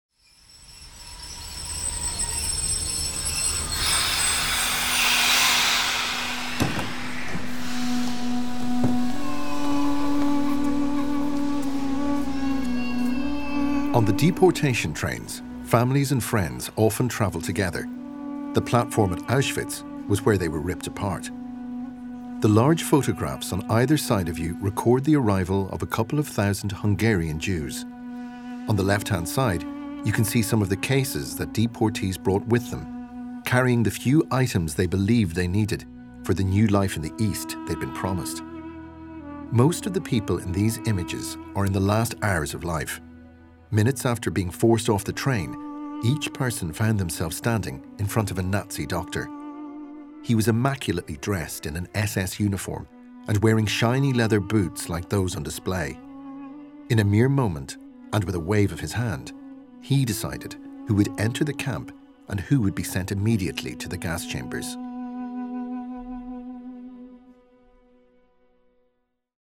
• Podcast/Audioguías